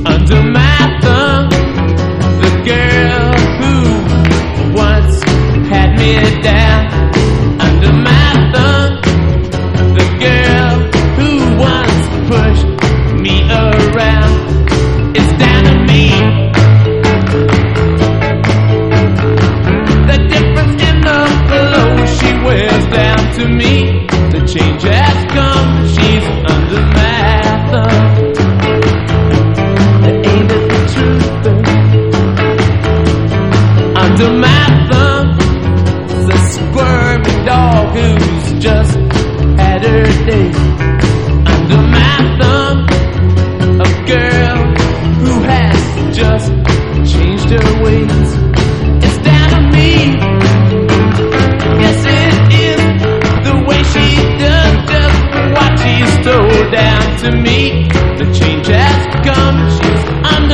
ROCK / 60'S / PSYCHEDELIC